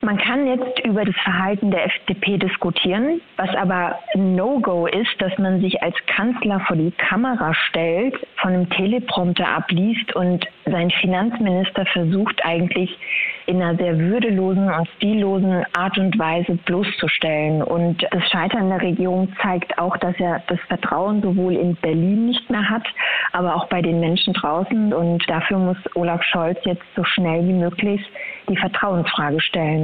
Die Mannheimer CDU-Bundestagsabgeordnete Melis Sekmen übte im SWR scharfe Kritik am Verhalten von Bundeskanzler Olaf Scholz.